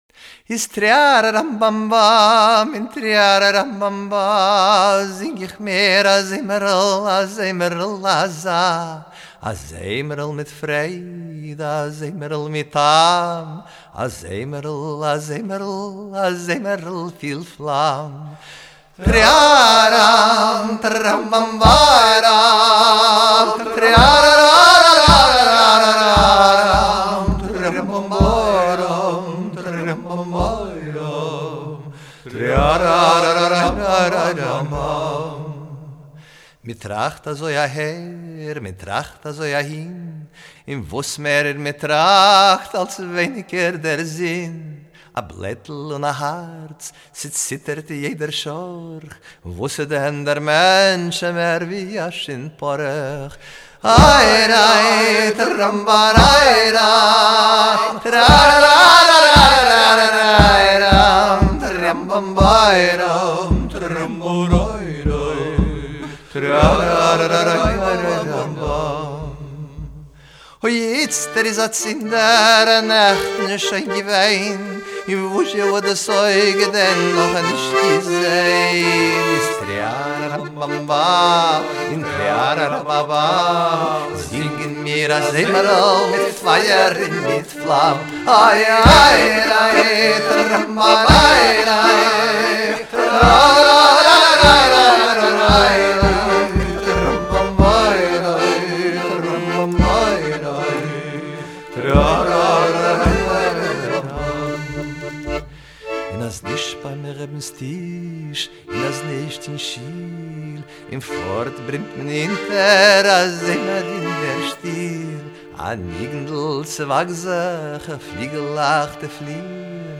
drum, accordion and multi-tracked voice